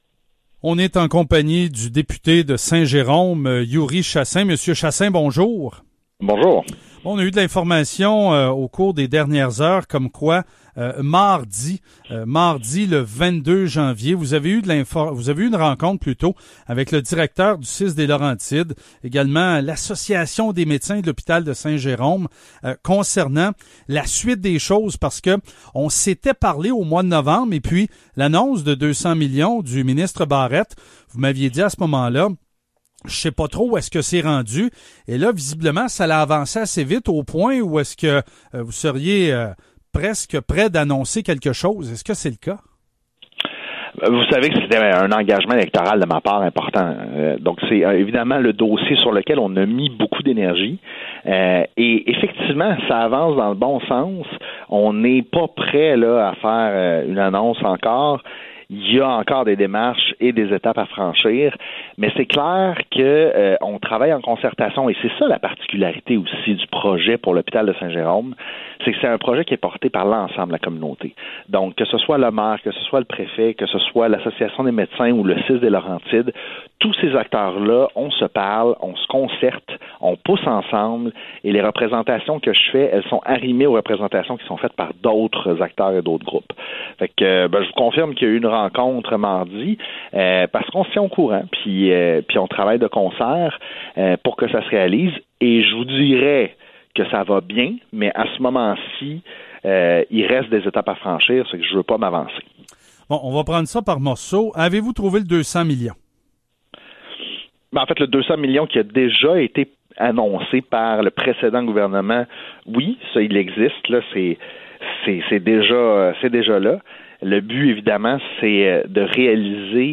Entrevue complète